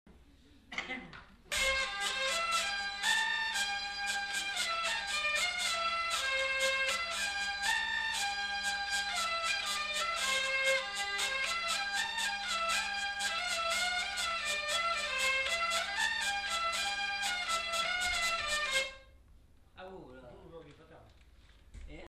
Lieu : Vielle-Soubiran
Genre : morceau instrumental
Instrument de musique : vielle à roue
Danse : quadrille